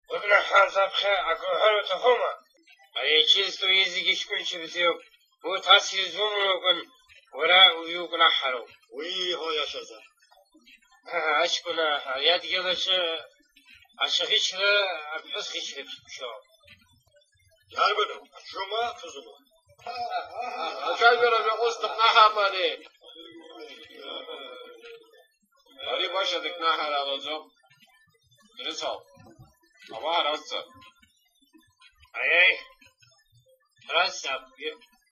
Sounds like a drunk man talking!
Lots of guttural sounds…